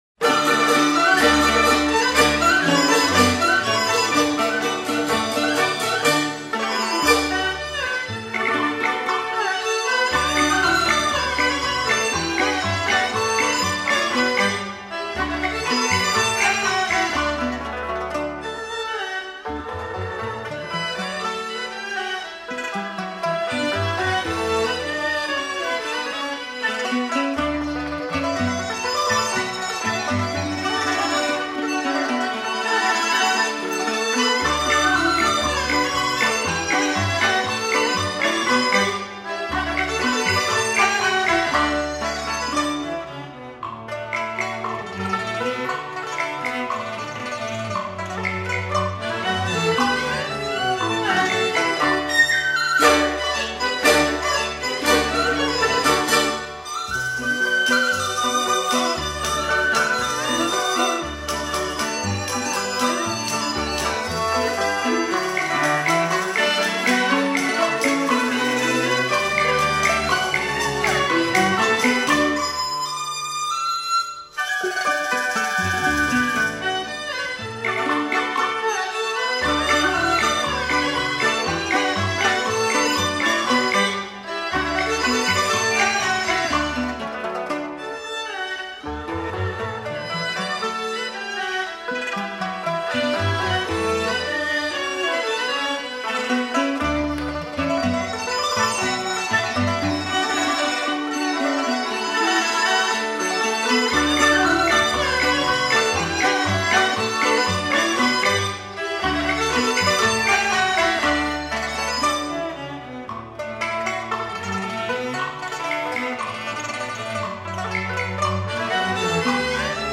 本碟汇集了近几十年来新创的中国民族音乐
只有中国乐器丝和竹的颤鸣才能纯净那无法压抑的欲望